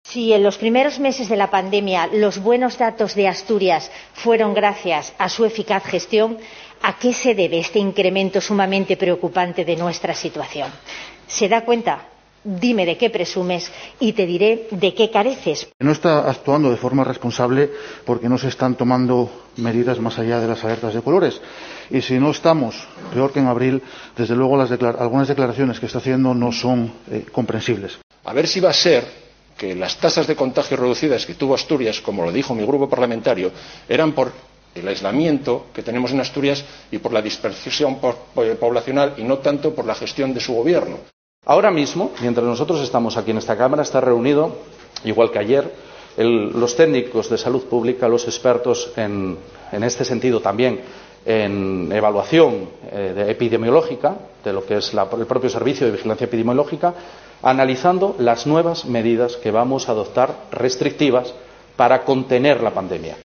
Debate sobre la pandemia en la Junta: Escucha a Mallada, Pumares, Blanco y Barbón